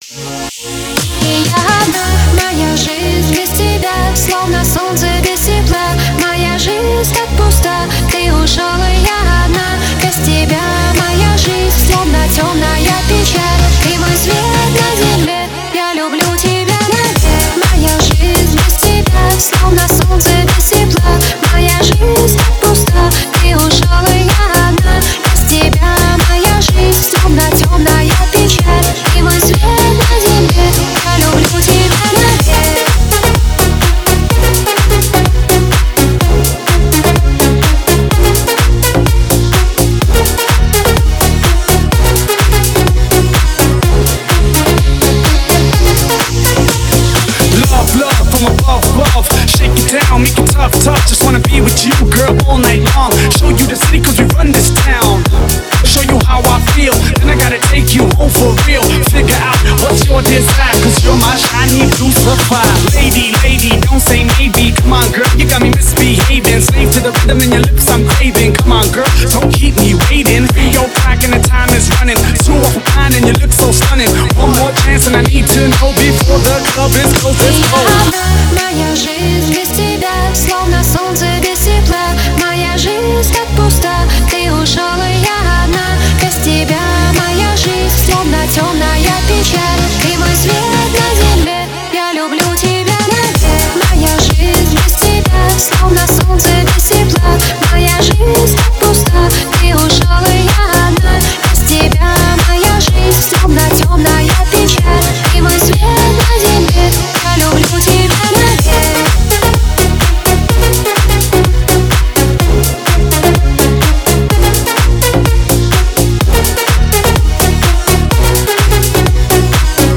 Жанр: Pop, Dance, Other